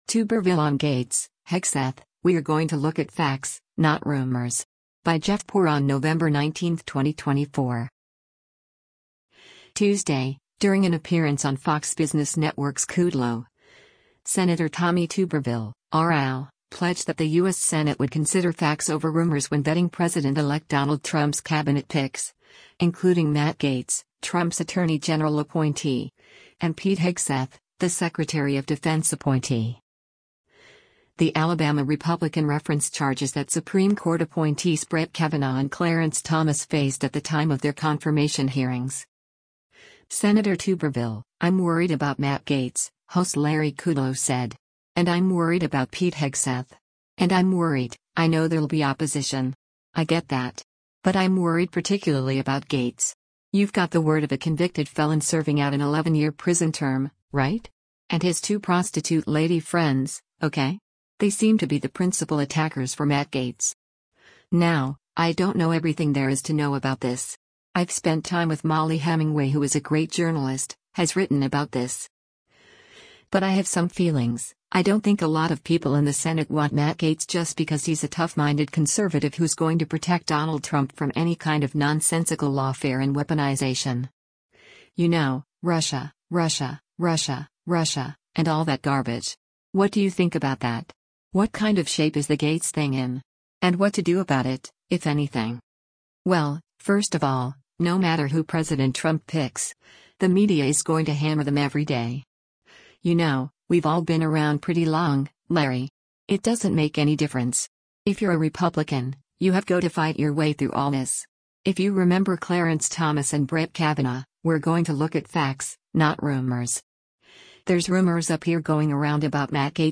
Tuesday, during an appearance on Fox Business Network’s “Kudlow,” Sen. Tommy Tuberville (R-AL) pledged that the U.S. Senate would consider facts over rumors when vetting President-elect Donald Trump’s Cabinet picks, including Matt Gaetz, Trump’s attorney general appointee, and Pete Hegseth, the Secretary of Defense appointee.